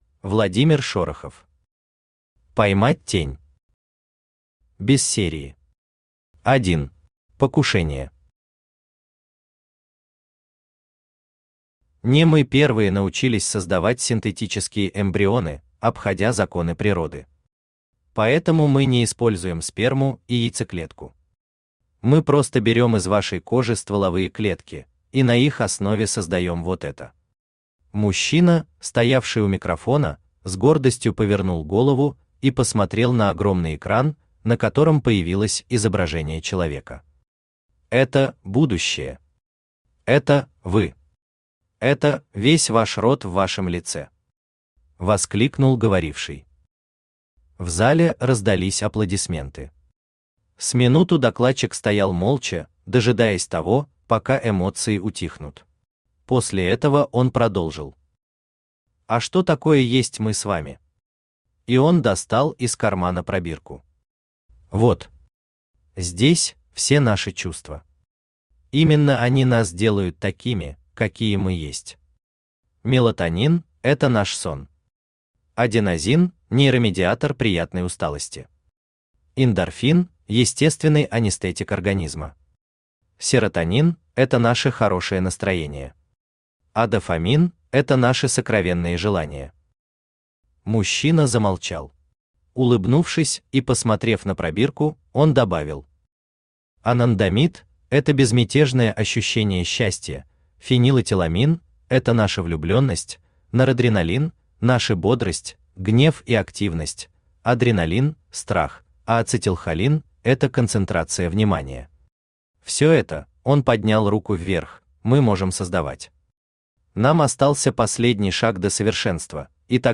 Aудиокнига Поймать тень Автор Владимир Леонидович Шорохов Читает аудиокнигу Авточтец ЛитРес.